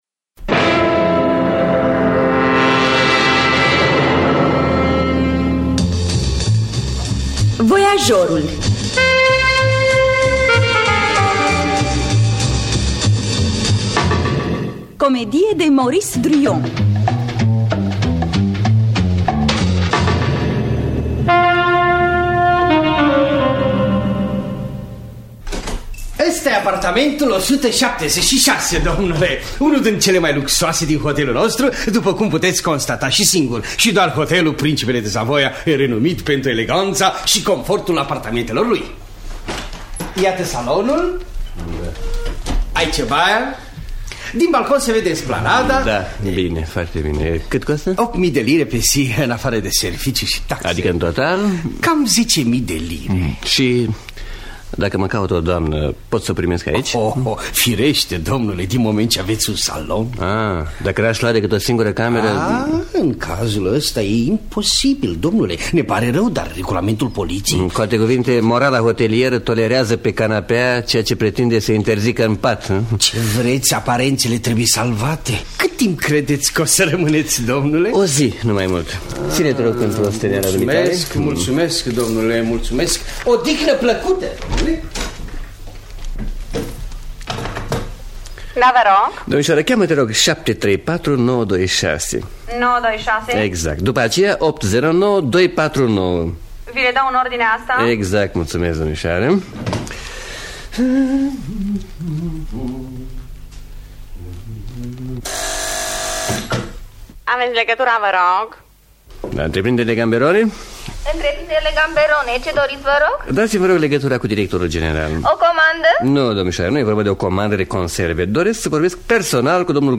Adaptarea radiofonică de Paul B. Marian.